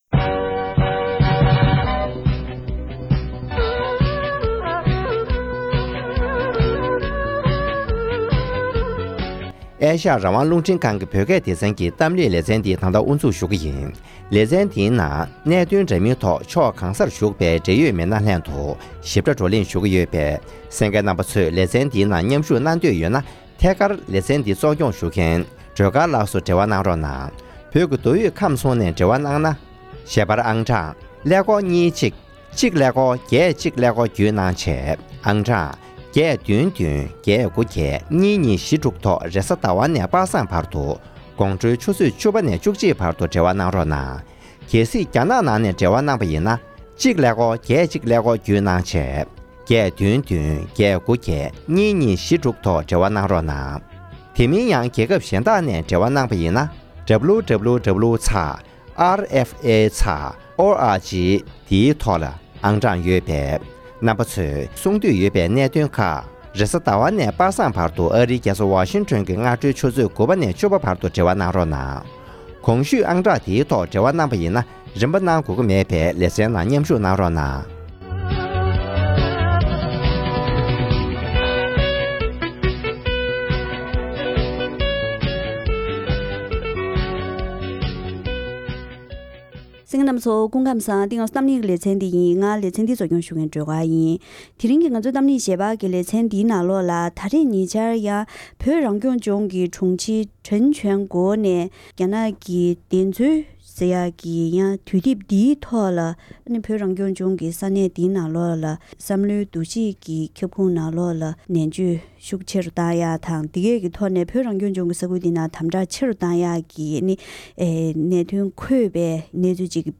༄༅། །དེ་རིང་གི་གཏམ་གླེང་ལེ་ཚན་ནང་། ཉེ་ཆར་བོད་རང་སྐྱོང་ལྗོངས་ཀྱི་དམར་ཤོག་ཚོགས་པའི་དྲུང་ཆེ་ གྲེན་ཇོན་གོ་ ཡིས་བོད་ནང་༧གོང་ས་མཆོག་གི་གསུང་ལ་སོགས་པའི་འབྲེལ་ཡོད་གནས་ཚུལ་ཆ་ཚང་བཀག་སྡོམ་བྱ་རྒྱུའི་སྐོར་ལ་རྩོམ་ཡིག་ཅིག་སྤེལ་ཡོད་པས། བོད་ནང་གི་གནས་སྟངས་སྐོར་ལ་འབྲེལ་ཡོད་མི་སྣ་ཁག་ཅིག་ལྷན་བཀའ་མོལ་ཞུས་པ་ཞིག་གསན་རོགས་གནང་།།